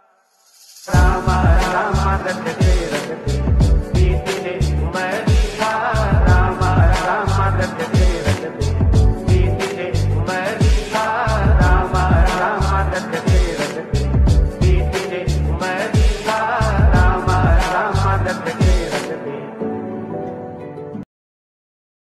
Bhakti Ringtones
Ram Bhajan Ringtone